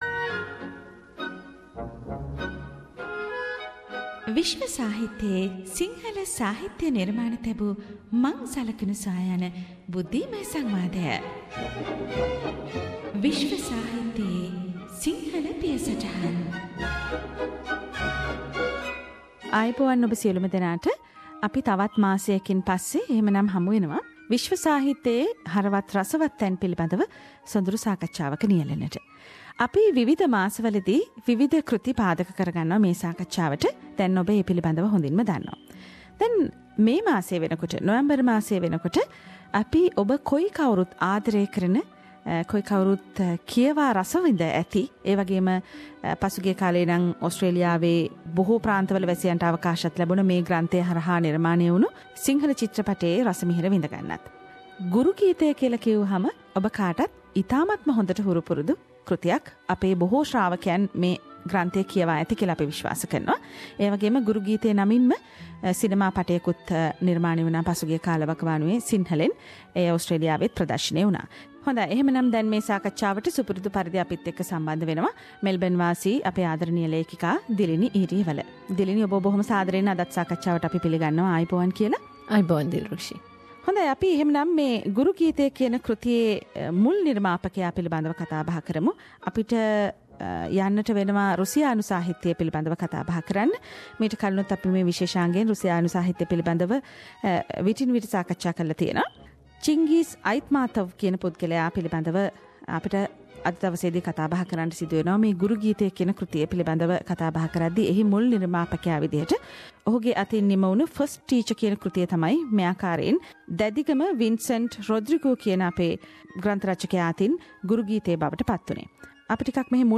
SBS Sinhalese brings you a comparative discussion of Russian author Chyngyz Aitmatovs First Teacher and the Sinhalese translation of First teacher - Guru Geethaya by writher Dadigama V. Rodrigo.